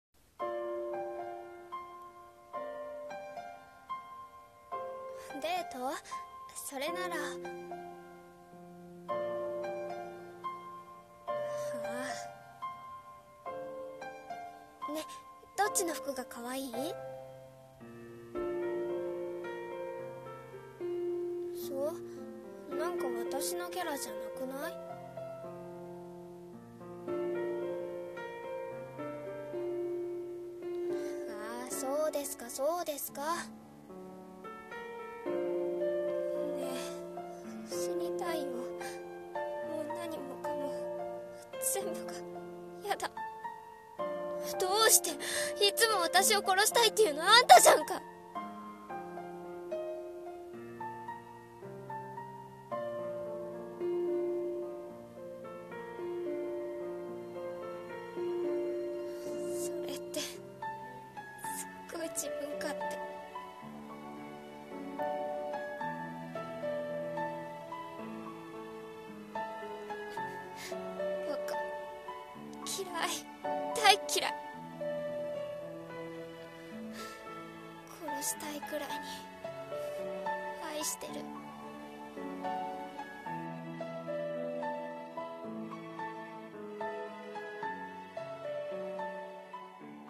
殺しあい【 声劇台本